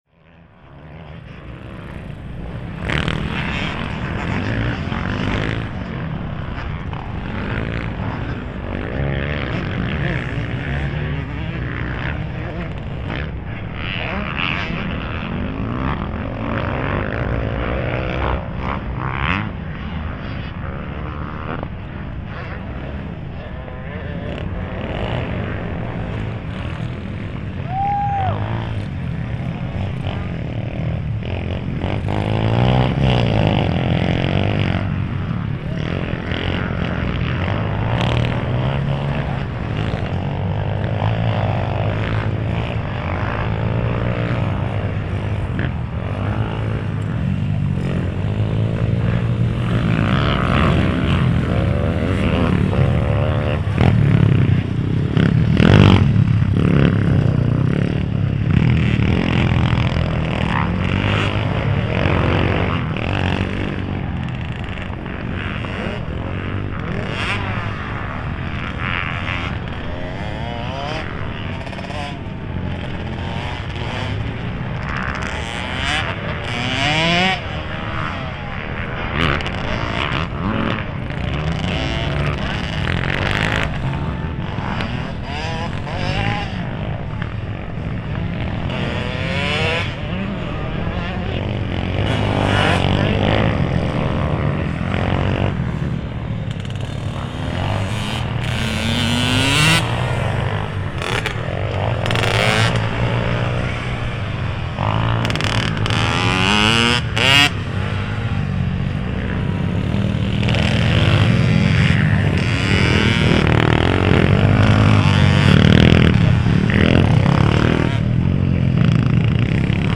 Dartford scrambler bikes
Tags: Sound Map in London London sounds UK Sounds in London London